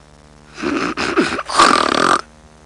Snoring (female) Sound Effect
Download a high-quality snoring (female) sound effect.
snoring-female.mp3